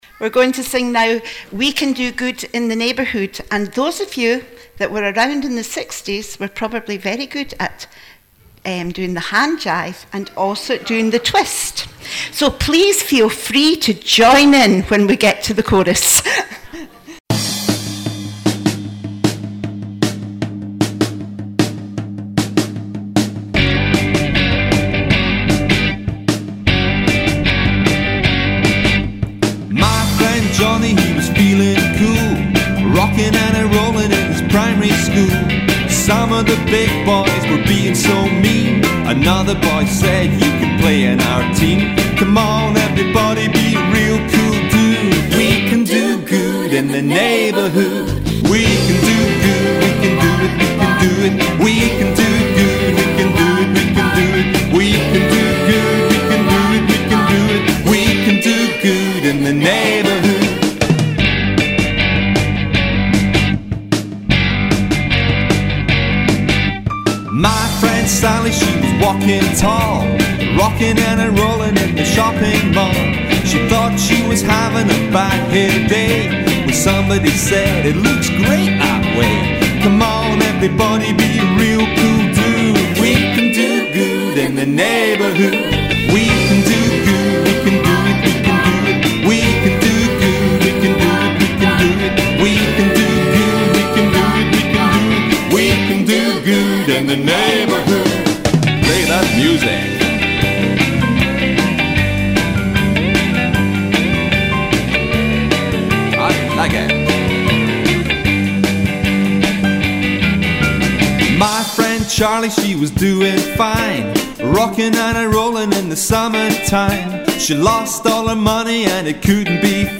Waste Watchers Holiday Club Family Service